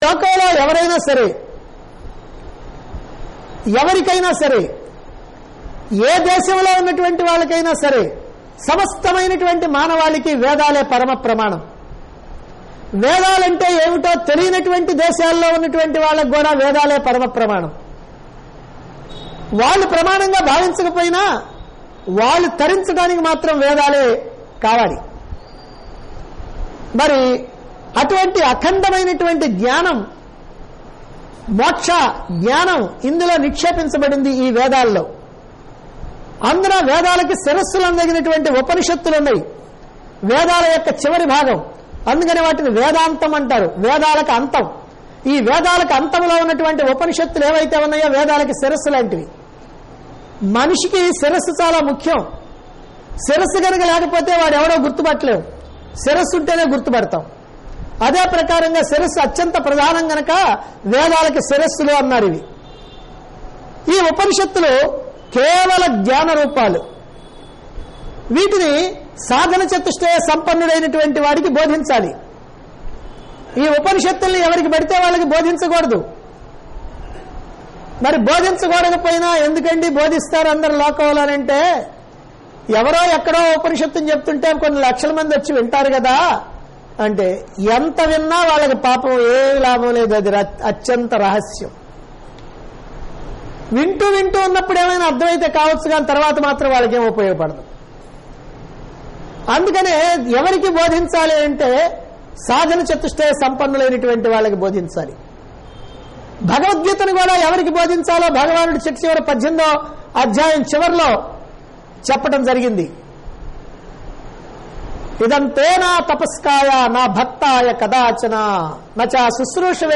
Discourse Conducted At Chilakaluripet, Guntur Dt. Andhra Pradesh.